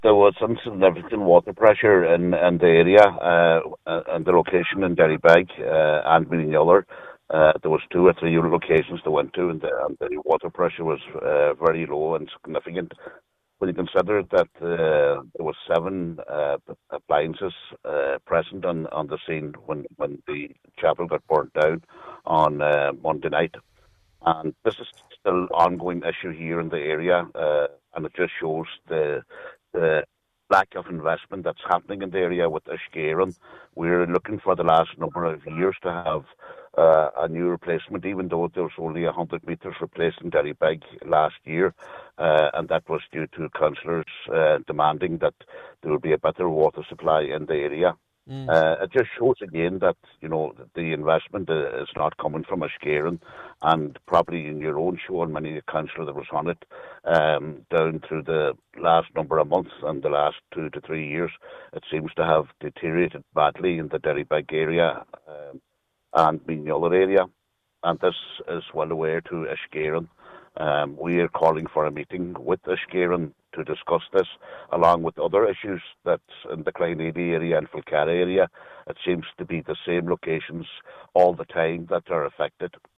Cllr John Sheamais O’Fearraigh says he was told this in recent days.